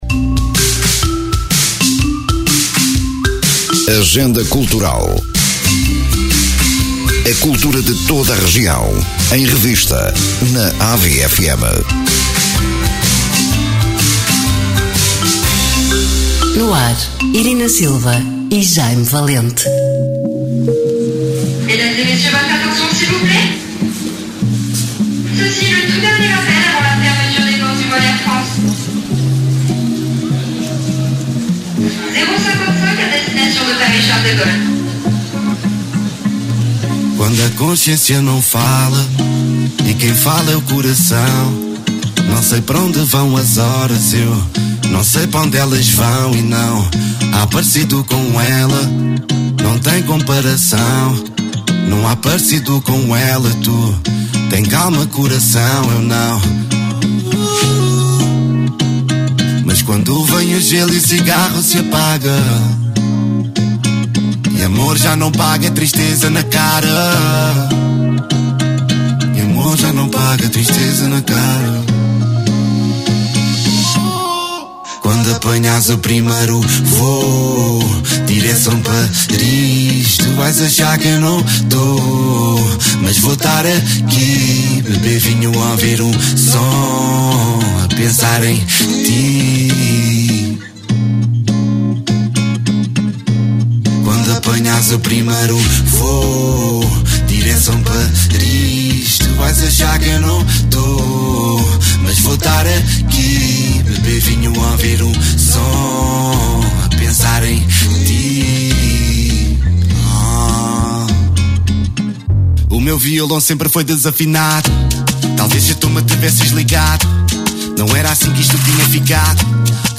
Emissão: 26 de Março 2024 Descrição: Programa que apresenta uma visão da agenda cultural de Ovar e dos Concelhos vizinhos: Estarreja, Feira, Espinho, Oliveira de Azeméis, São João da Madeira, Albergaria-a-Velha, Aveiro e Ílhavo. Programa com conteúdos preparados para ilustrar os eventos a divulgar, com bandas sonoras devidamente enquadradas.